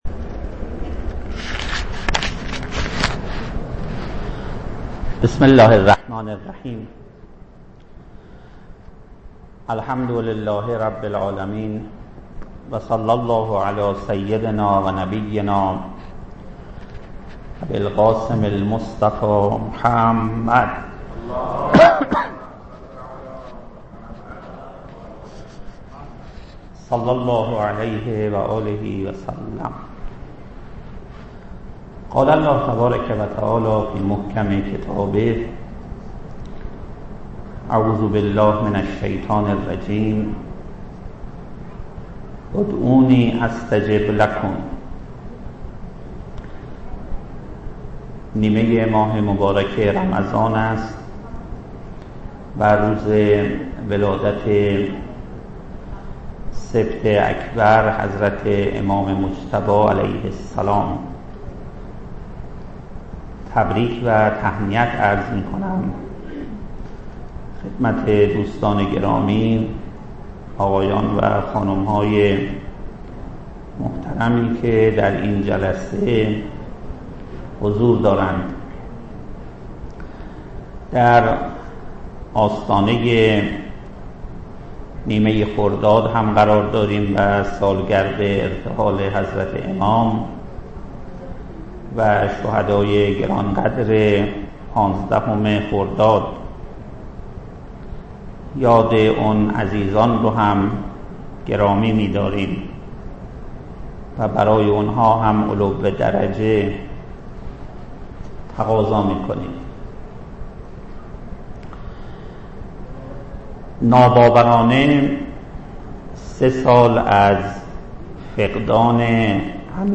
گزارش شفقنا از سخنرانی نیمه ماه رمضان ۹۷ + فایل صوتی